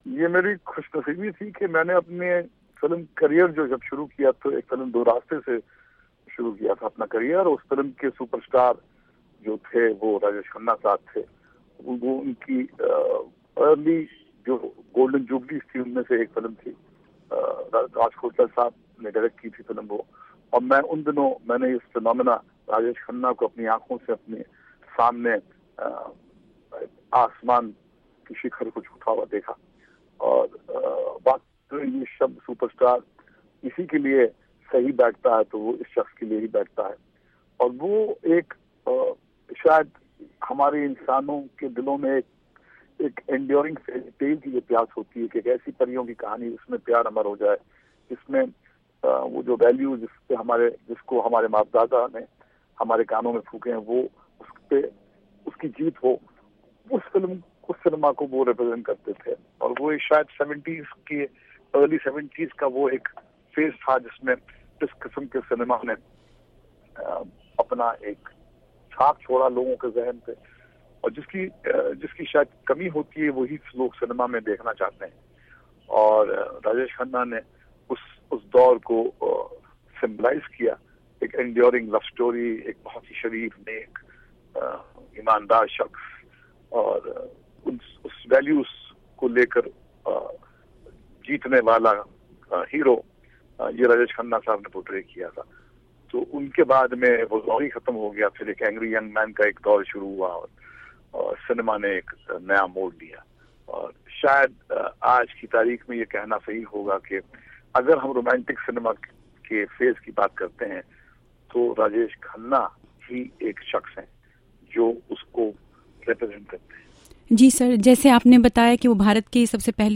بالی ووڈ کے مشہور ڈائریکٹر اور پروڈیوسر مہیش بھٹ نے ’وائس آف امریکہ‘ سے گفتگو میں راجیش کھنہ کی فلمی خدمات کو سراہتے ہوئے کہا ہےکہ وہ انڈسٹری کے اُس وقت کے حقیقی سپر سٹار تھے جب سنیما اپنے عروج کی طرف بڑھ رہا تھا ۔